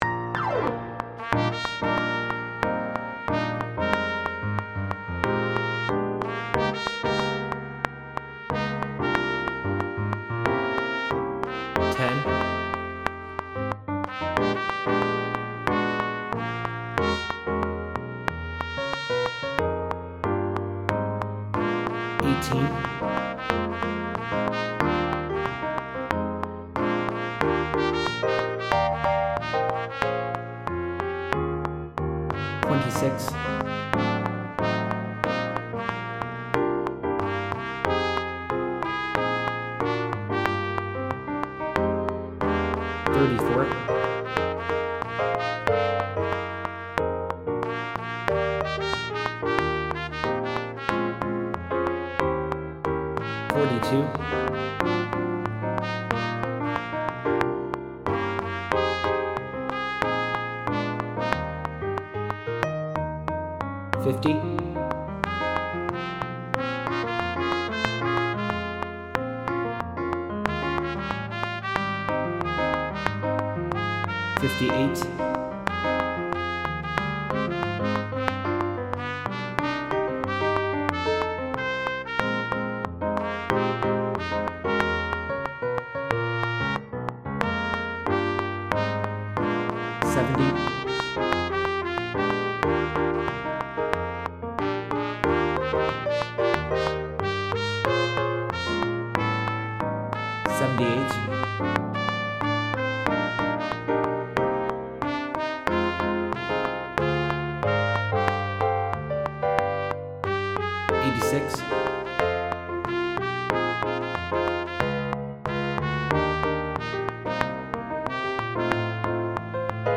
Sop 2